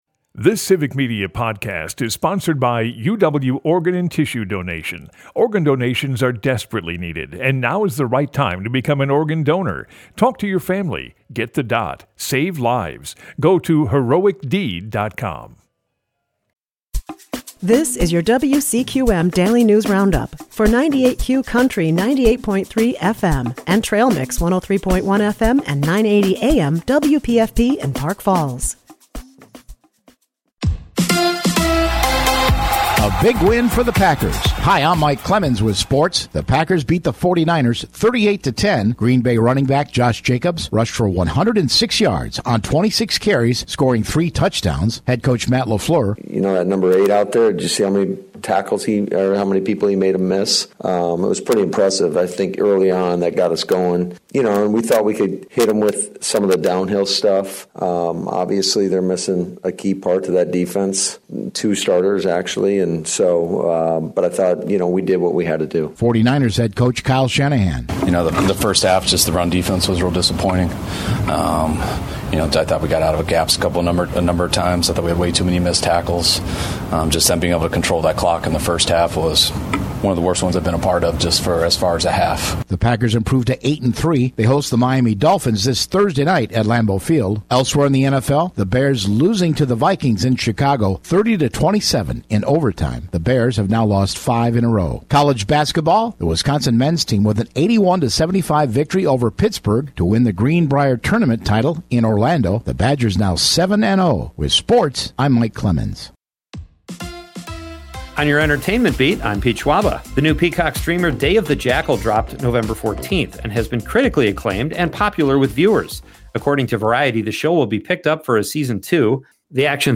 98Q Country WCQM and WPFP have your state and local news, weather, and sports for Park Falls, delivered as a podcast every weekday.